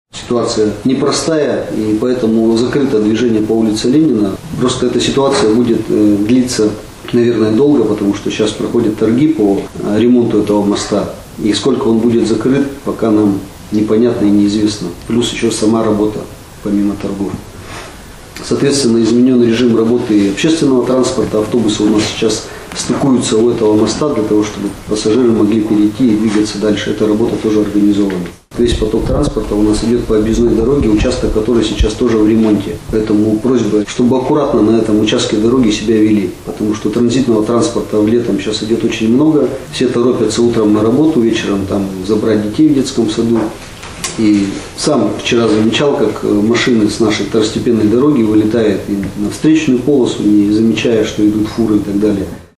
Сегодня глава Аромашевского района Евгений Ковальчук в ходе совещания с руководителями организаций и предприятий сообщил о том, что проходят торги по ремонту моста.
Glava_raĭona_situatsiia_s_zakrytiem_mosta_v_Aromashevo.mp3